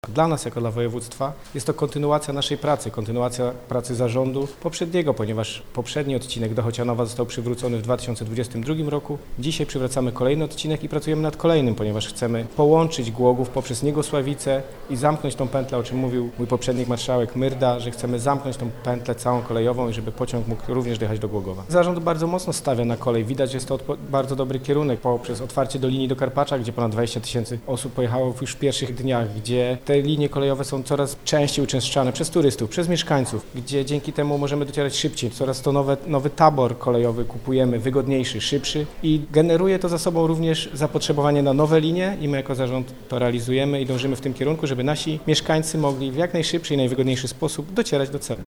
O inwestycji mówi Michał Rado, wicemarszałek województwa dolnośląskiego.